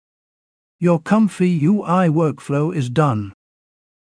example_tts.wav